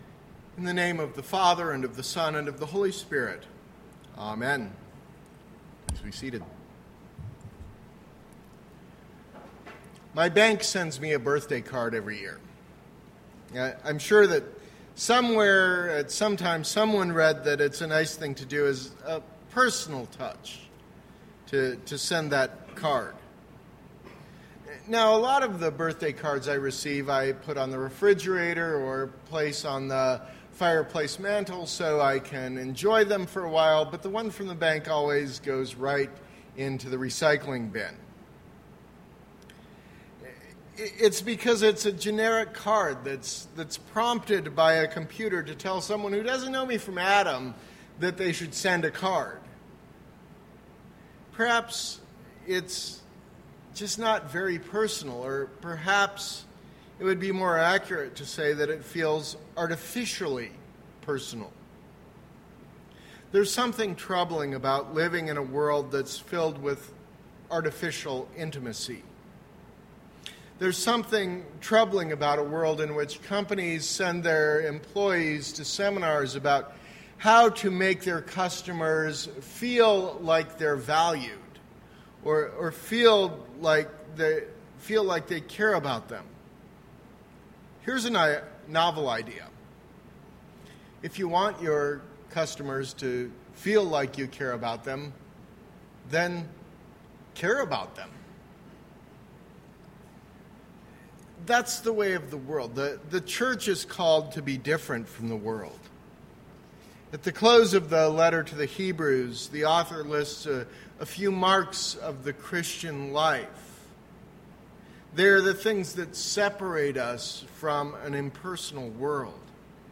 Sermon – August 28, 2016